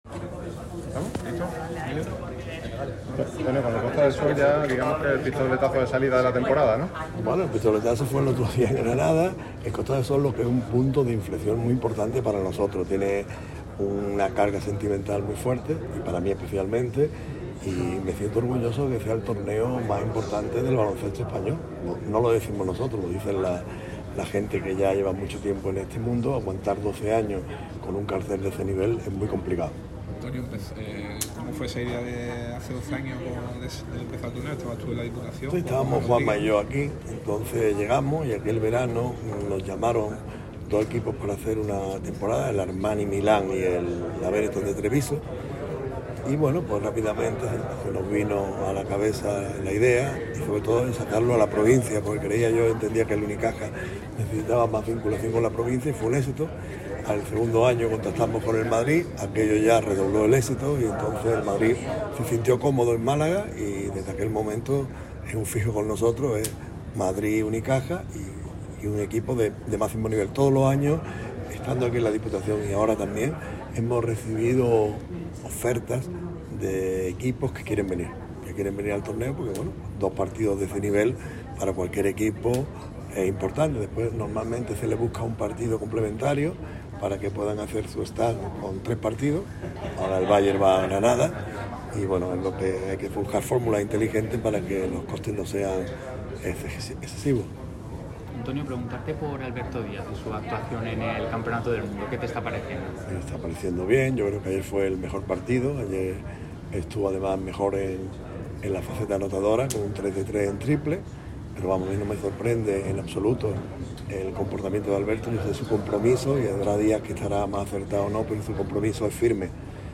en la presentación del Torneo Costa del Sol, que tuvo lugar en la Diputación de Málaga